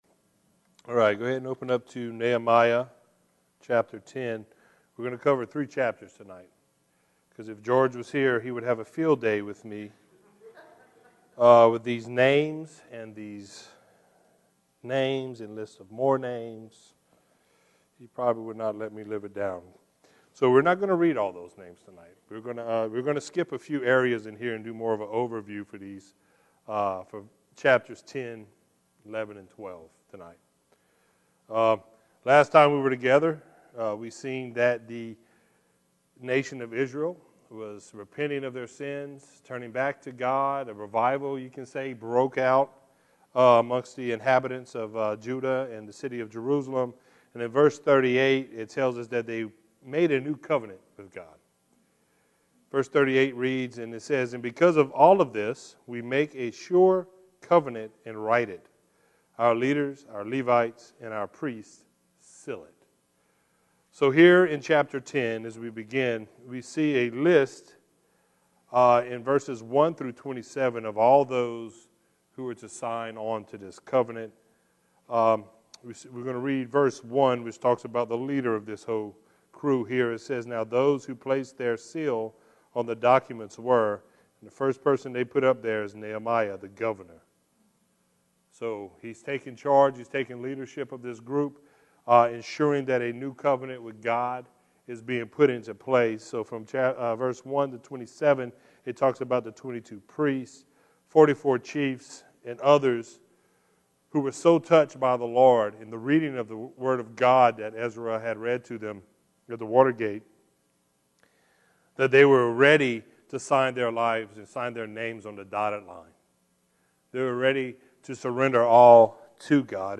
verse by verse study